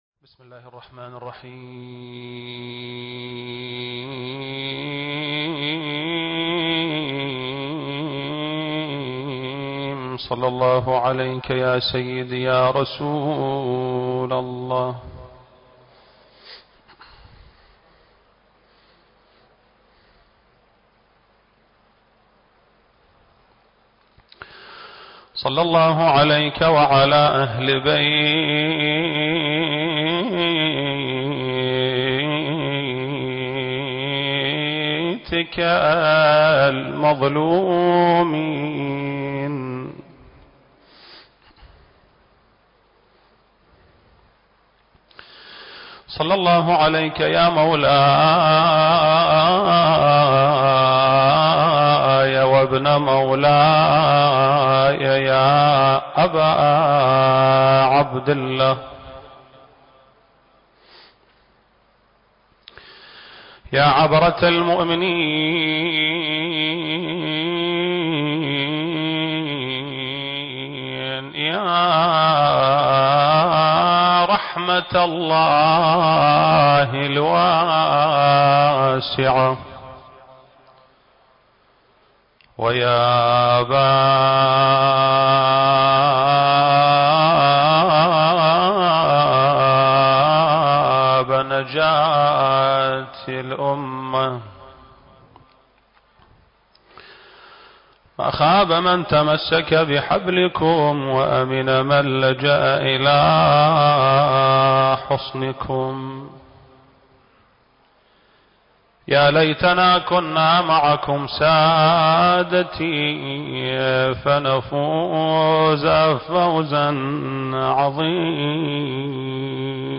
المكان: مسجد آل محمد (صلّى الله عليه وآله وسلم) - البصرة التاريخ: شهر رمضان المبارك - 1442 للهجرة